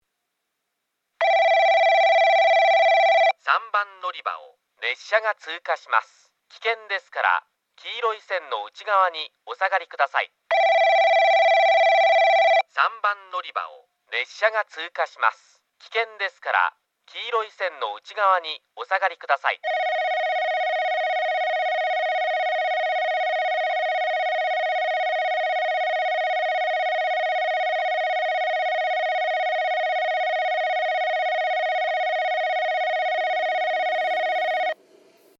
3番のりば通過放送＋接近ベル
放送はJACROS簡易詳細型です。
スピーカーは0，1がカンノボックス型、2，3がカンノや円型ワイドホーン、TOAラッパ型です。